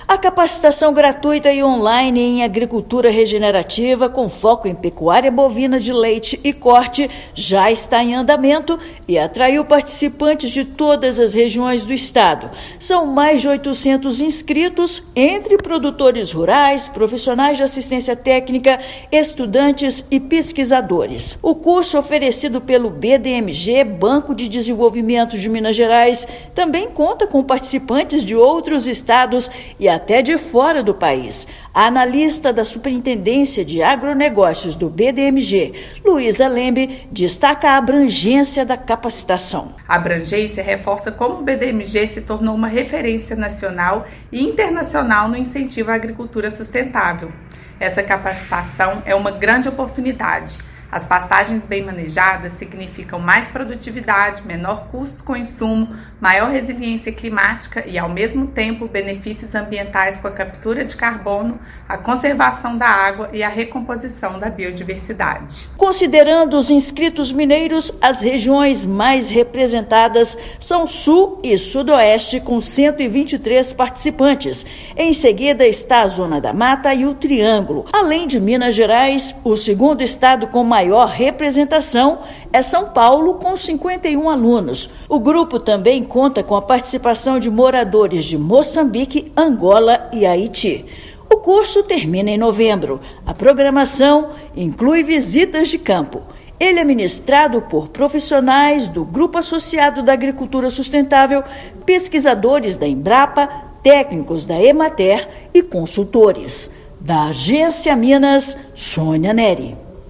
Alunos de Minas Gerais, outros 20 estados e até de fora do país vão aprender como a inclusão de práticas sustentáveis torna a propriedade mais competitiva. Ouça matéria de rádio.